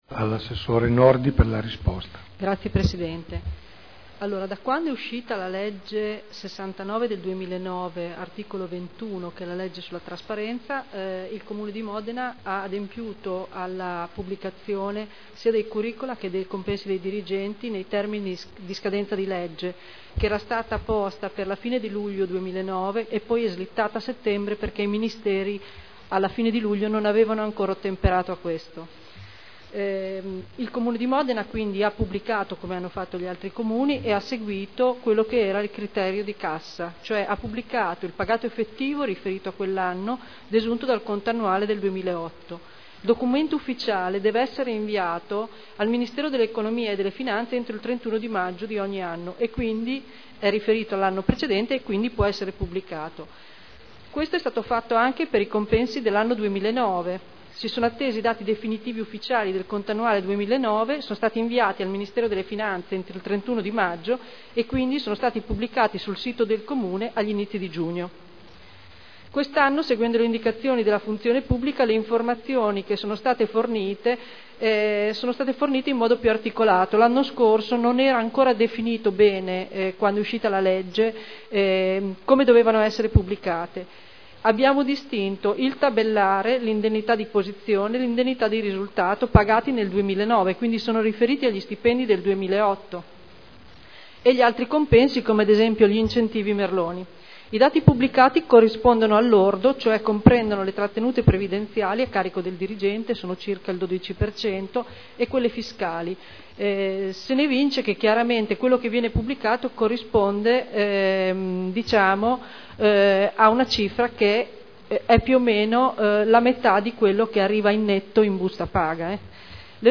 Marcella Nordi — Sito Audio Consiglio Comunale